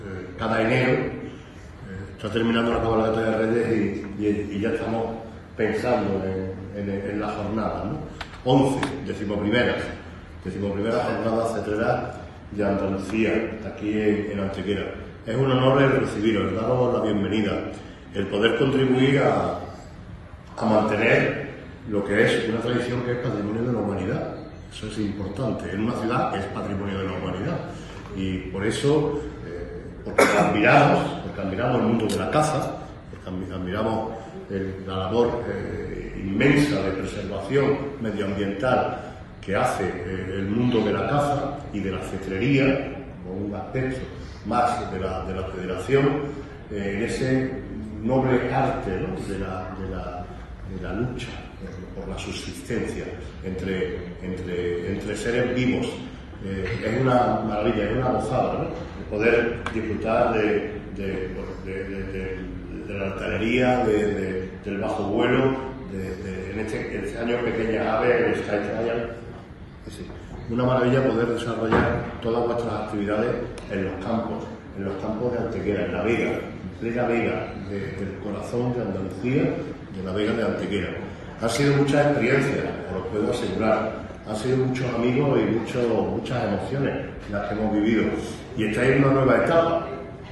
El Salón de Plenos del Ayuntamiento ha acogido un acto en el que también han estado presentes los tenientes de alcalde Alberto Arana y Antonio García, sirviendo como antesala de las competiciones a desarrollar durante sábado y domingo en las inmediaciones del camino de Mataliebres junto al anejo de Los Llanos.
El alcalde Manolo Barón ha destacado durante su intervención la condición como Patrimonio Inmaterial de la UNESCO del noble arte milenario de la cetrería.
Cortes de voz